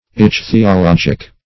Search Result for " ichthyologic" : The Collaborative International Dictionary of English v.0.48: Ichthyologic \Ich`thy*o*log"ic\, Ichthyological \Ich`thy*o*log"ic*al\, a. [Cf. F. ichthyologique.]